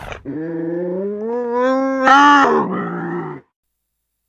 Здесь вы найдете реалистичные аудиозаписи: от мягкого мурлыканья до грозного рычания.
Звуки лесного кота